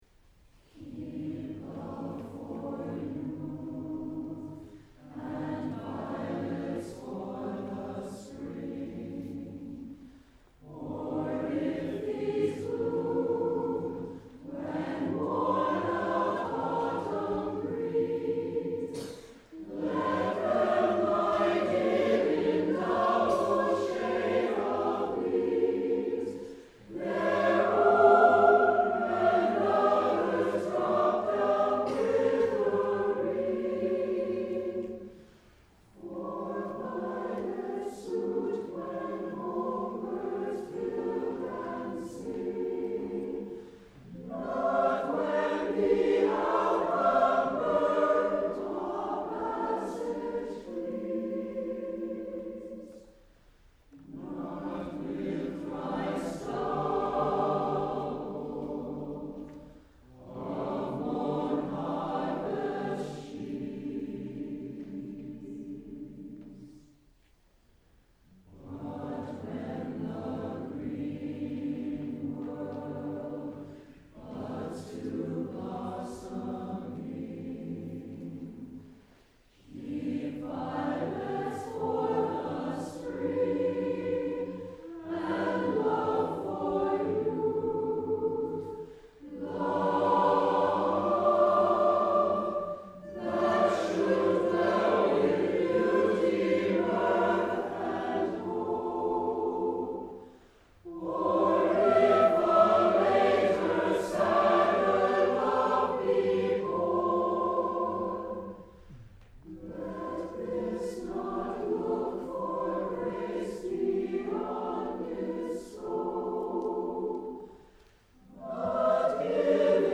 Voicing: SATBB